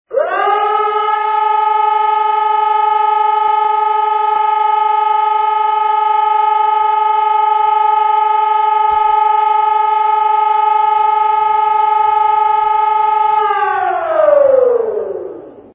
Sirenensignale
2. Sirenenprobe:
Ein Dauerton von 15 Sekunden bedeutet "Sirenenprobe". Dieses Signal dient nur zum Testen der Sirenenfunktion und wird in der Regel jeden Samstag Mittag um 12 Uhr ausgelöst.
alarm_probe.mp3